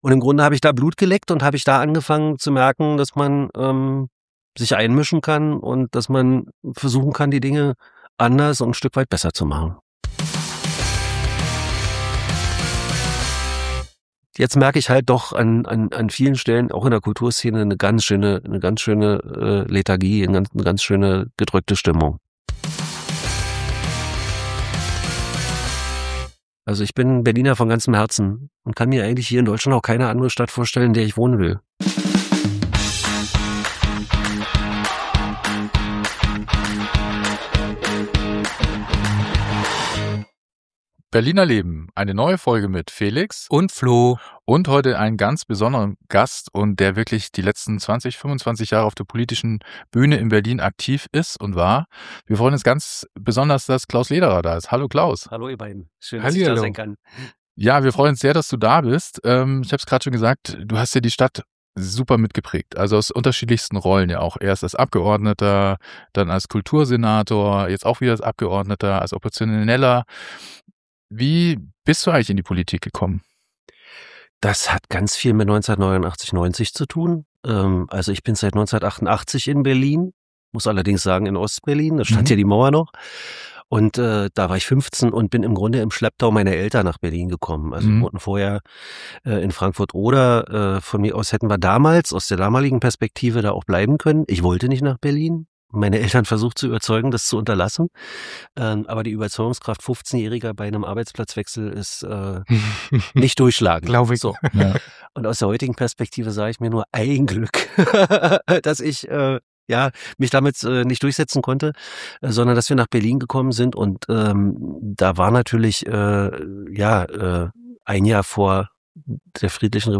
Wir treffen Klaus Lederer am Alexanderplatz.
Der perfekter Ort für ein Gespräch mit ihm.